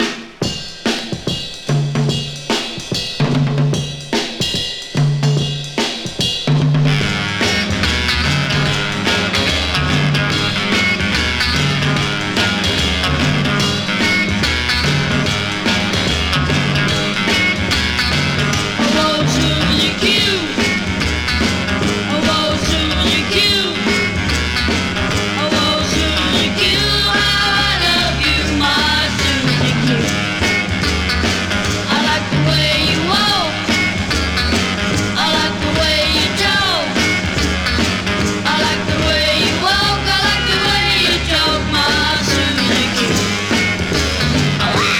Rock, Pop, Garage　USA　12inchレコード　33rpm　Mono